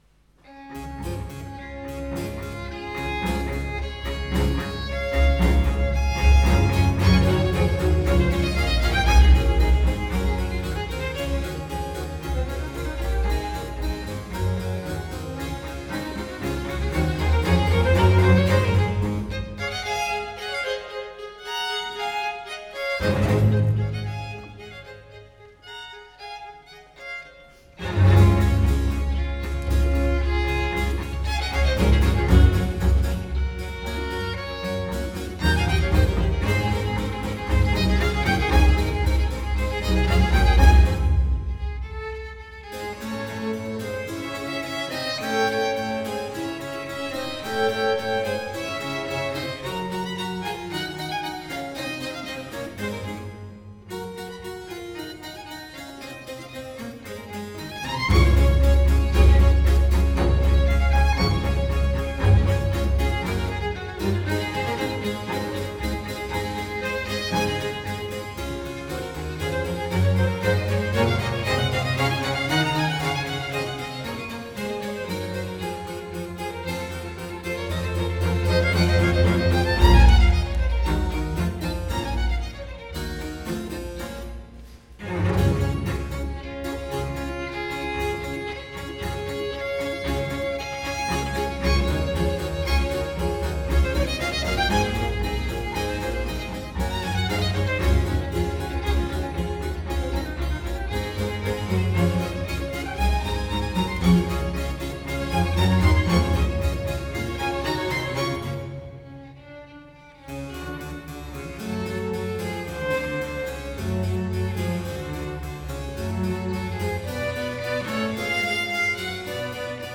Live recordering 07 ottobre 2017